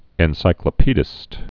(ĕn-sīklə-pēdĭst)